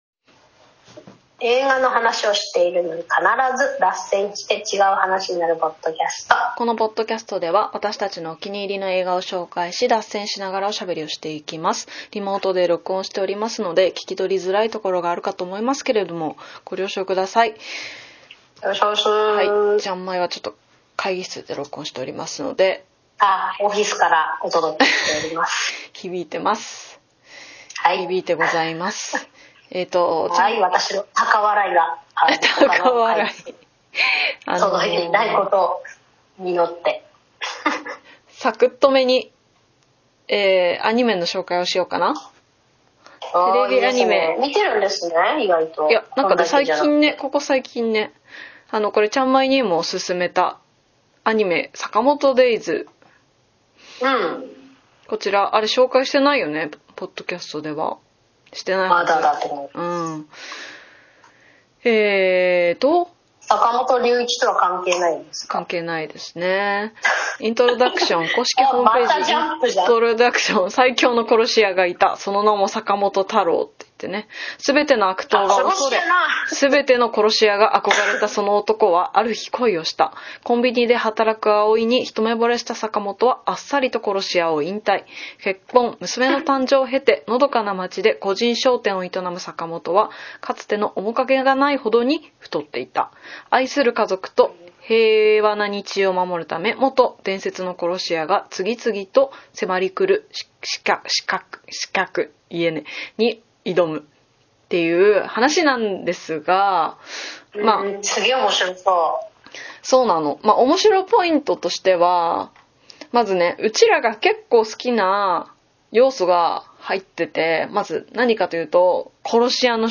台本・編集ナシのおしゃべりポッドキャストです。(現在はリモート収録中)毎週月曜日に更新しています。